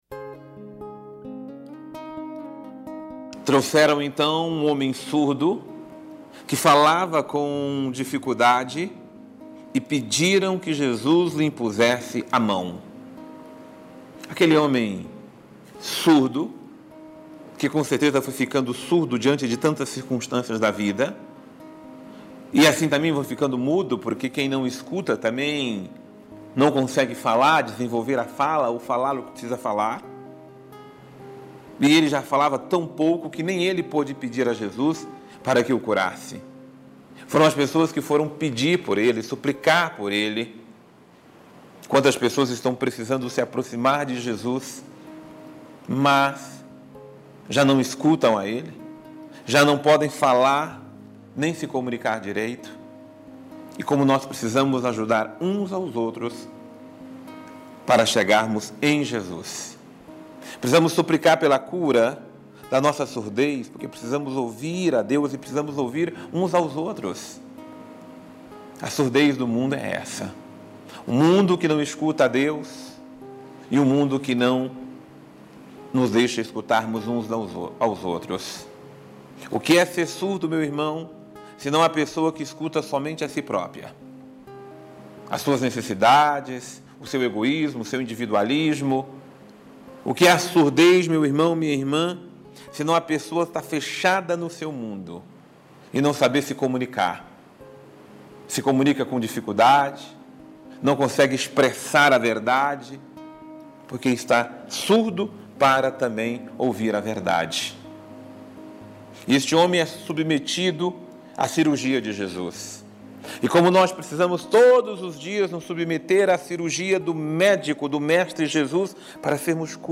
Homilia Diária - Abra todo o seu ser para ser curado por Jesus